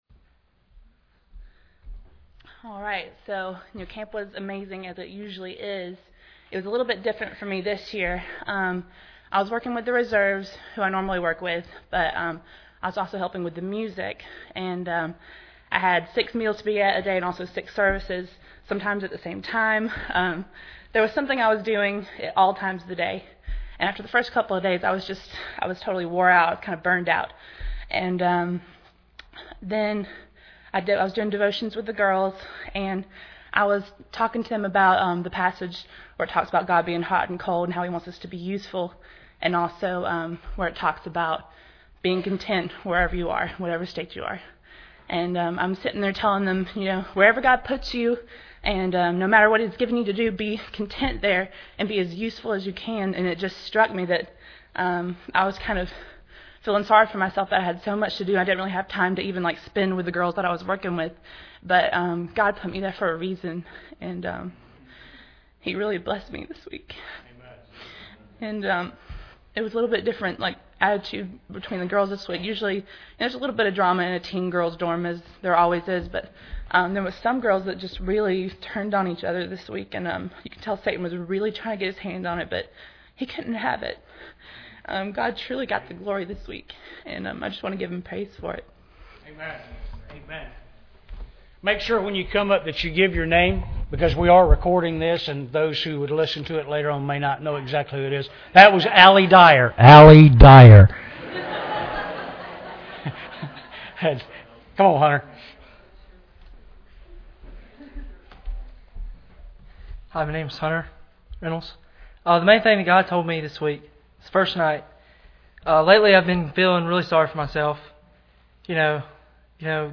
NGM Camp Testimonies 2013
Preacher: CCBC Members | Series: General